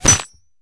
bullet_impact_glass_07.wav